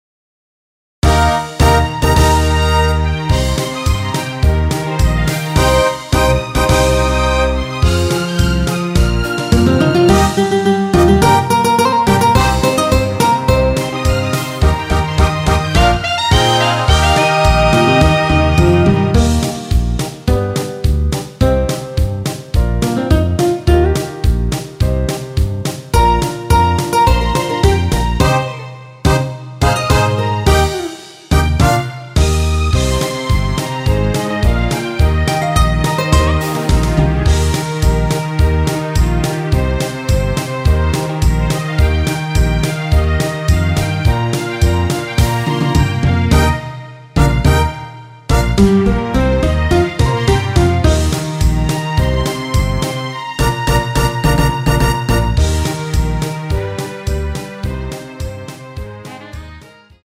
MR 입니다.
Eb
앞부분30초, 뒷부분30초씩 편집해서 올려 드리고 있습니다.
중간에 음이 끈어지고 다시 나오는 이유는